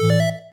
Techmino/media/effect/chiptune/connect.ogg at b3ca43fa696bcea94ae6b670adb26efb44fe7c21
connect.ogg